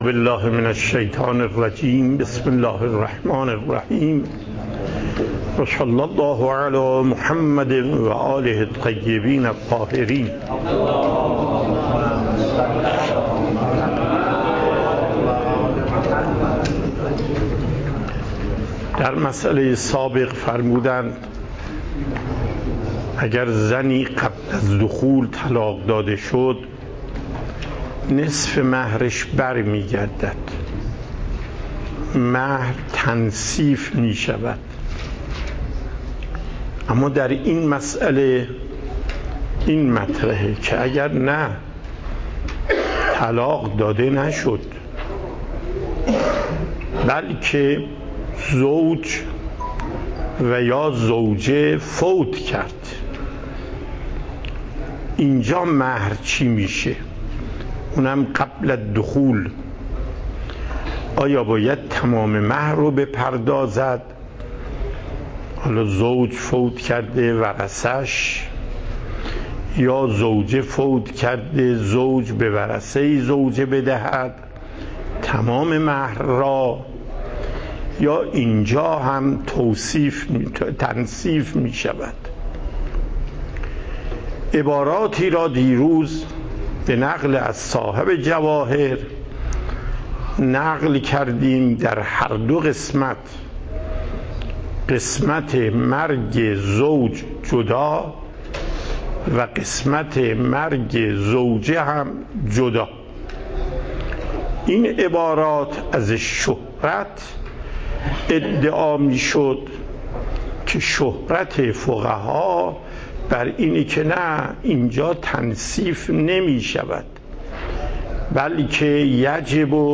پخش زنده صوت درس + دریافت صوت و تقریر درس